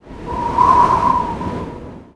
fx_pcm_mono_wind01.wav